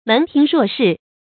注音：ㄇㄣˊ ㄊㄧㄥˊ ㄖㄨㄛˋ ㄕㄧˋ
門庭若市的讀法